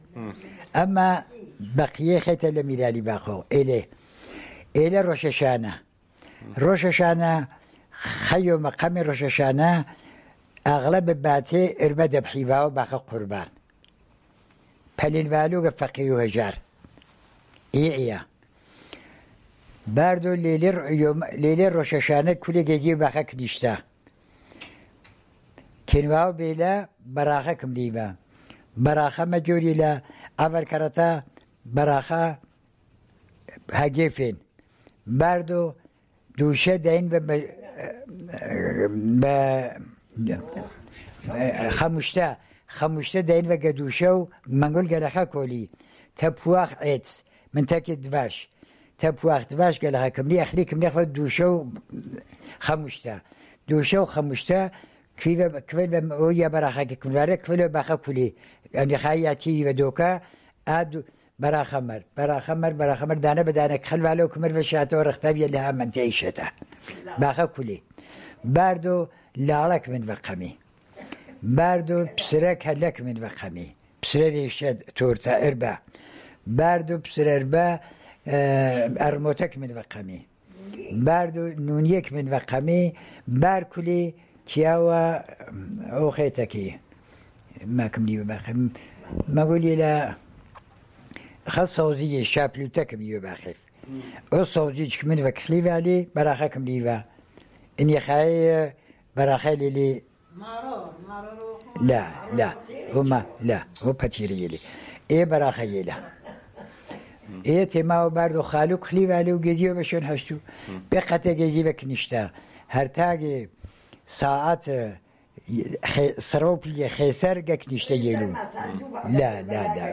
Sanandaj, Jewish: New Year